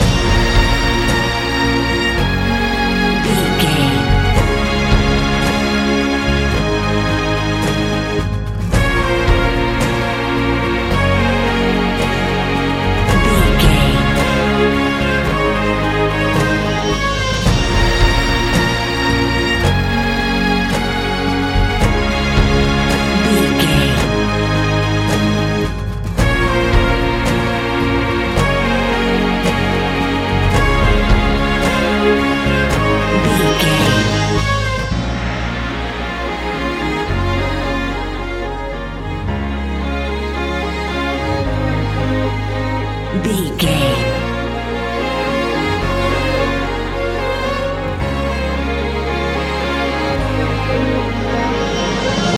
Epic / Action
Uplifting
Aeolian/Minor
B♭
orchestra
strings
acoustic guitar